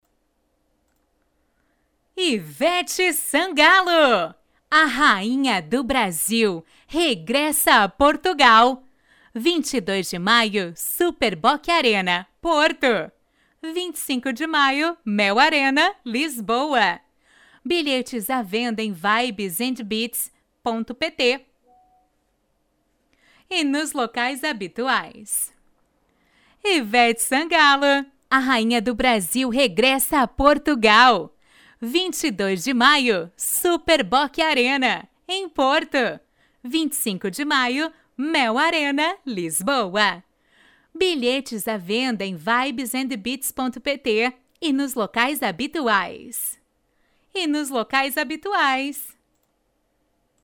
VOZ BEM MACIA E SUAVE!!!